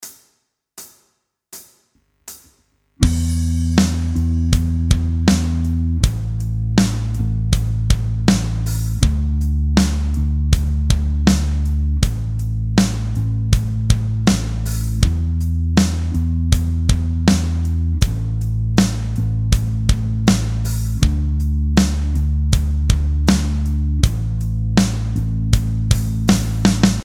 Chord Progression: E – A – D – A
Backing-Track-1-2.mp3